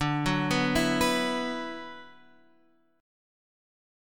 D6add9 chord